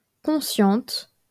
wymowa:
IPA/kɔ̃.sjɑ̃t/